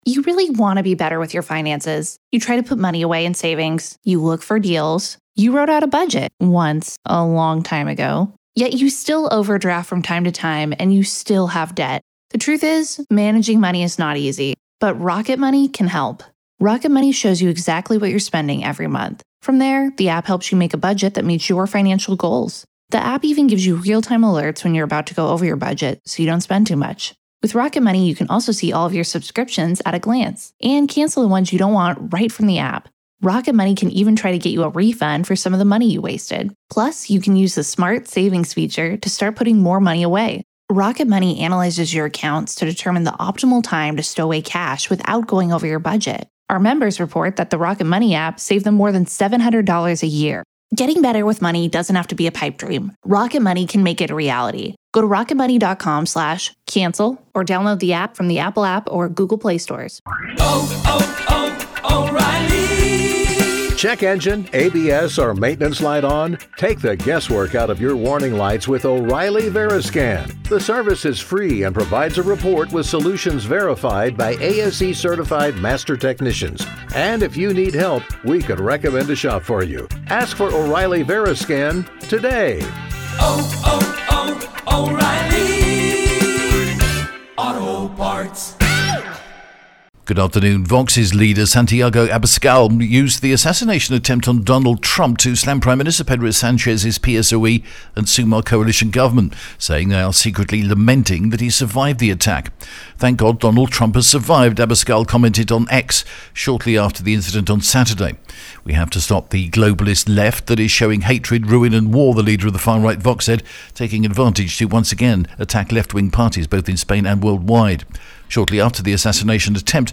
TRE is the only broadcaster in Spain to produce and broadcast, twice daily its own, in house, Spanish and local news service in English...and we offer this to you as a resource right here, and on the hour at tre.radi...